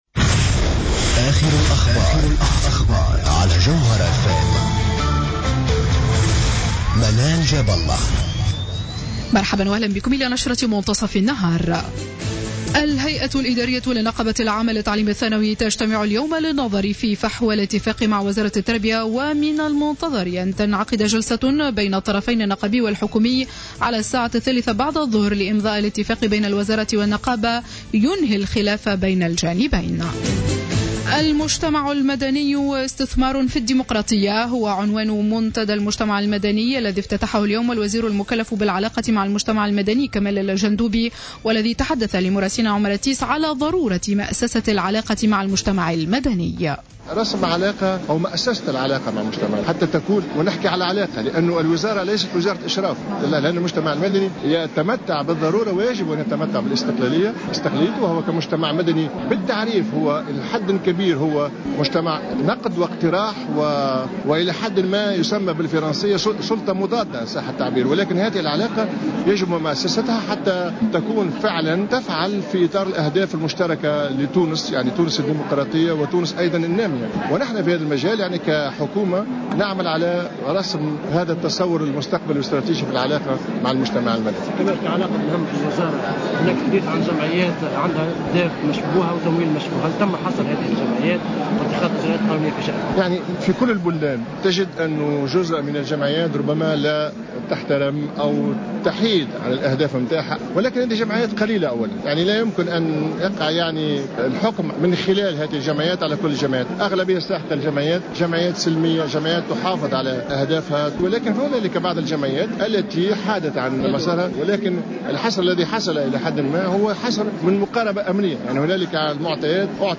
نشرة أخبار منتصف النهار ليوم السبت 04 أفريل 2015